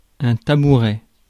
Ääntäminen
Synonyymit boursette bourse-à-pasteur bourse-à-berger Ääntäminen France: IPA: /ta.bu.ʁɛ/ Haettu sana löytyi näillä lähdekielillä: ranska Käännös Ääninäyte Substantiivit 1. stool US 2. footstool Suku: m .